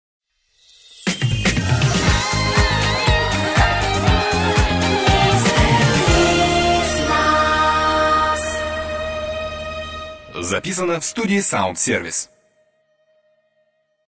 Джингл